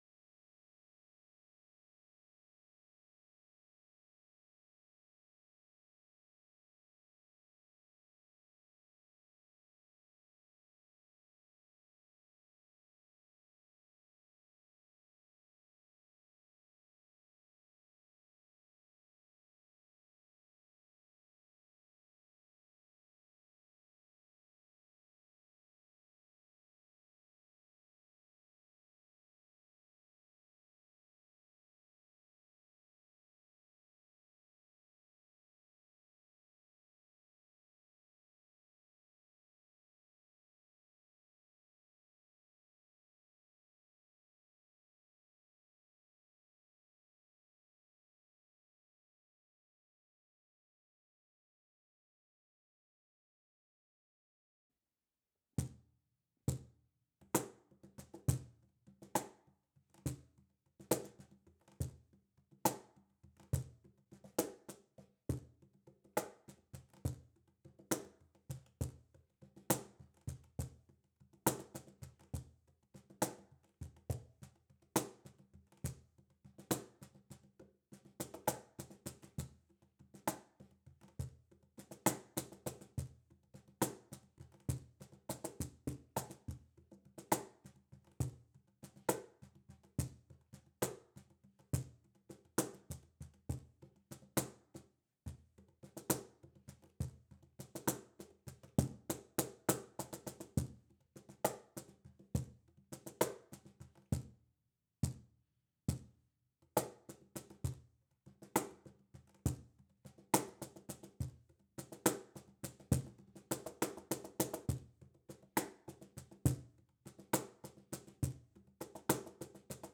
Toward Tomorrow - Cajon - Stereo.wav